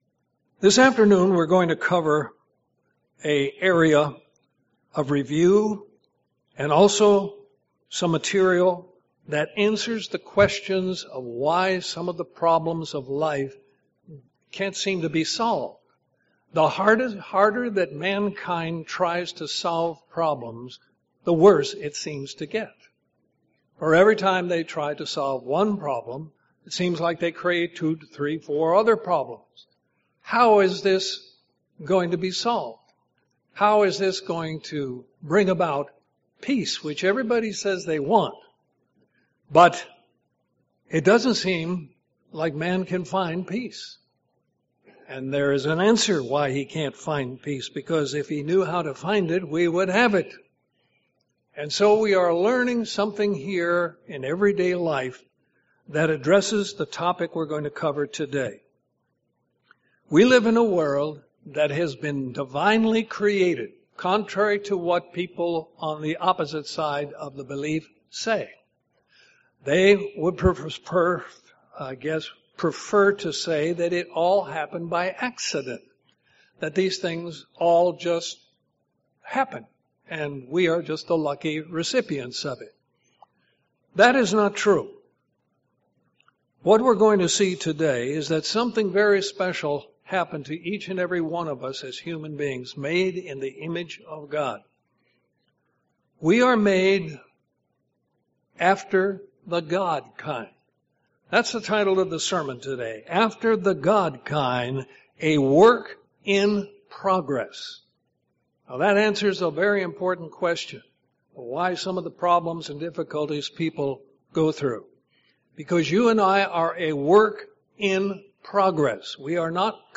Sermons
Given in Columbus, GA